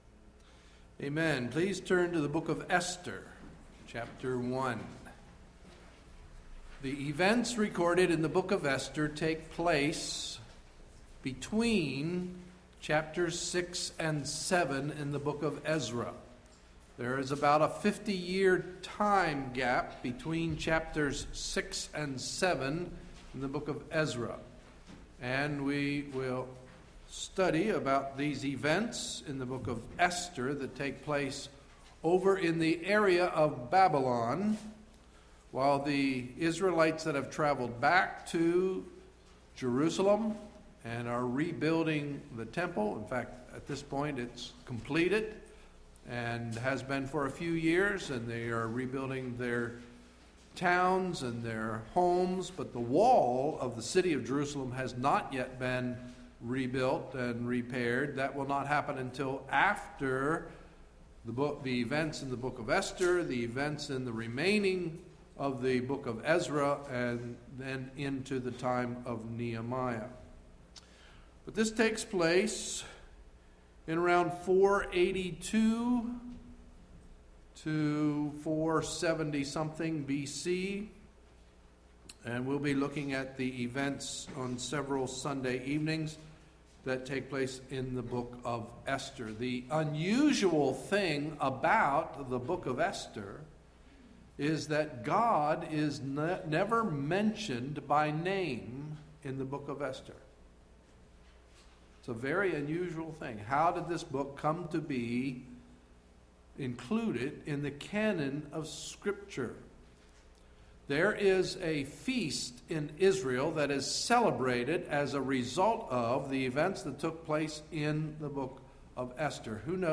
Sunday, March 27, 2011 – Evening Message